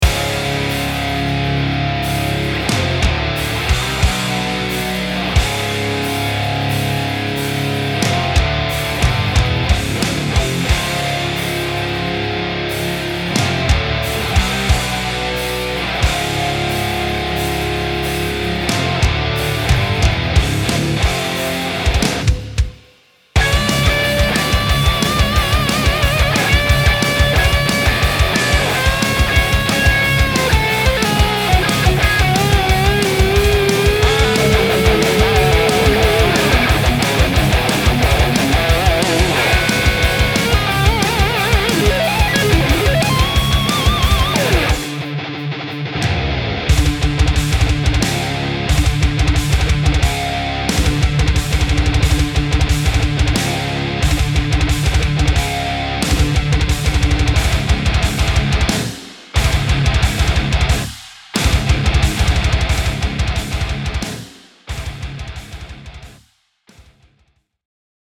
Il humbucker Riff Raff ha una risposta dei bassi serrata e incisiva con un morso morbido nella fascia alta; l'epitome della voce del rock'n'roll dei primi anni '60.
Sebbene doppiato come un humbucker rock vintage, i musicisti hard rock e metal apprezzeranno il suono incisivo e concentrato se usato con rig ad alto guadagno.